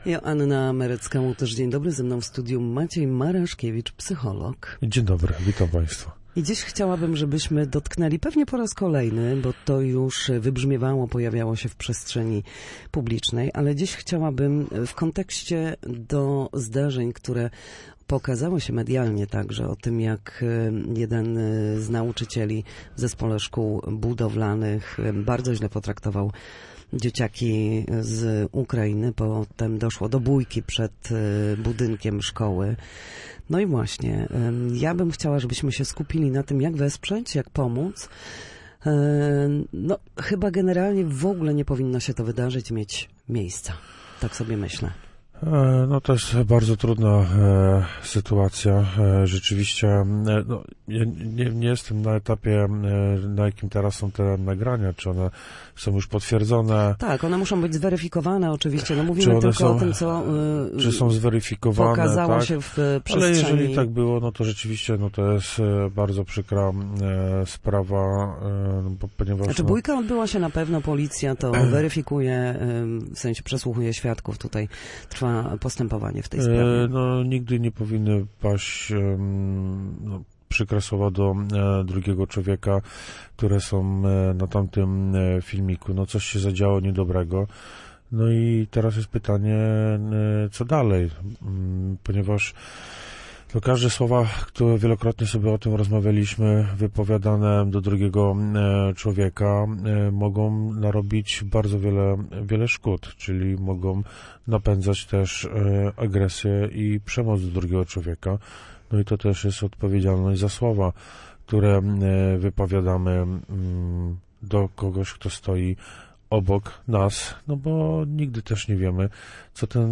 W każdą środę w popołudniowym Studiu Słupsk Radia Gdańsk dyskutujemy o tym, jak wrócić do formy po chorobach i urazach. W audycji „Na zdrowie” nasi goście, lekarze i fizjoterapeuci, odpowiadają na pytania dotyczące najczęstszych dolegliwości, podpowiadają, jak wyleczyć się w domowych warunkach i zachęcają do udziału w nowych, ciekawych projektach.